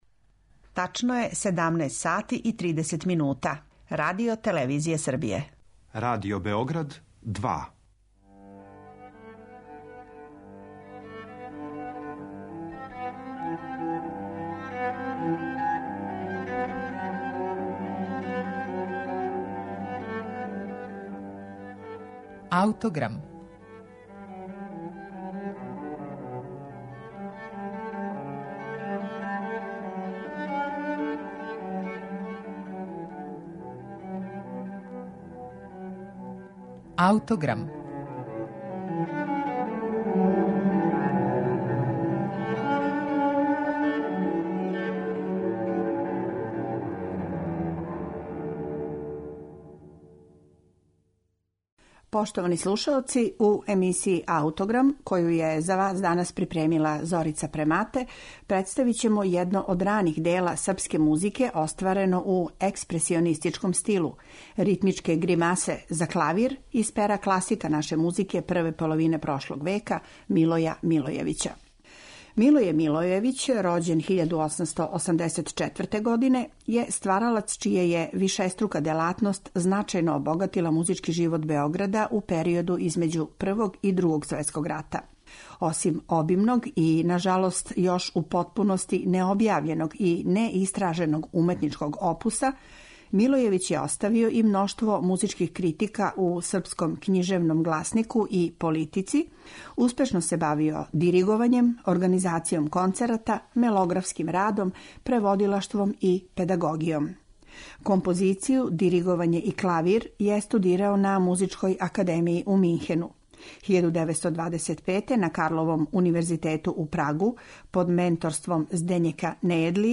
Емисија Аутограм петком је, по традицији, посвећена домаћем музичком стваралаштву. Имаћете прилику да чујете једно од дела које за нашу музику има историјски значај и вредност, клавирску свиту „Ритмичке гримасе".
Милоје Милојевић је ово своје остварење написао 1937. године, као једну од првих композиција српске музике остварену у експресионистичким стилу. Данас се „Ритмичке гримасе" свирају веома ретко, па наш снимак потиче из 1996, када су оне биле последњи пут јавно изведене и снимљене, а у оквиру Међународне трибине композитора.